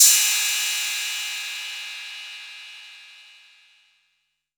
808CY_1_Orig.wav